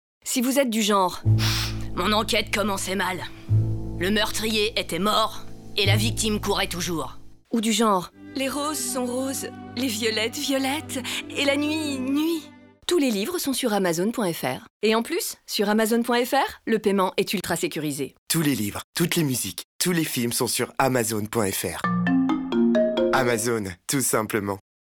Démo Pub
Voix off